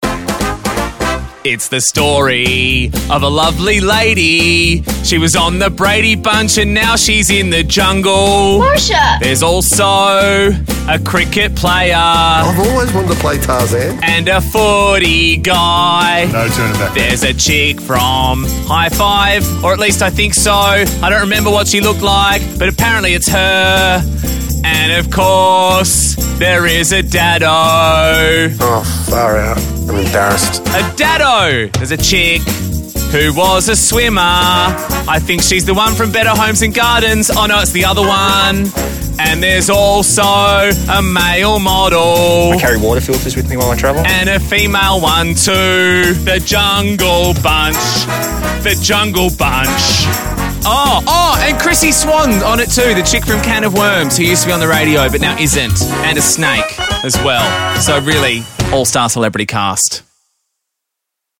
catchy
cover